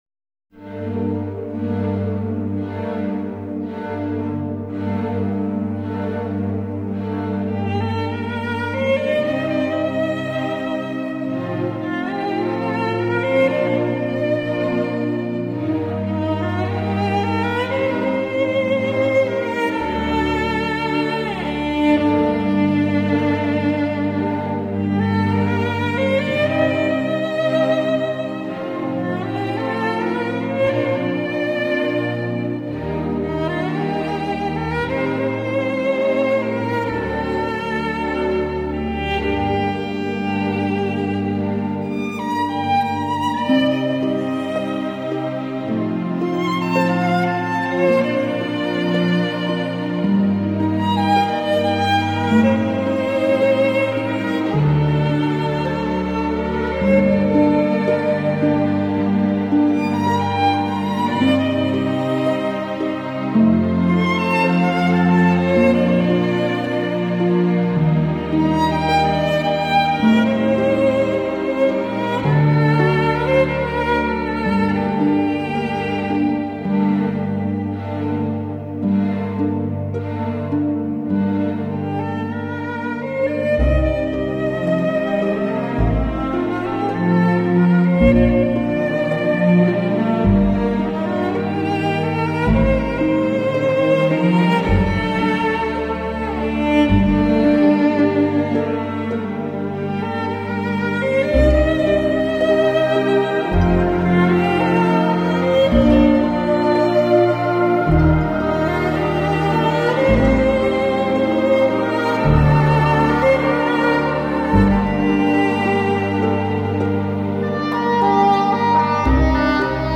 [1730] 차분해 지고 싶을 때 들으면 좋은 노래..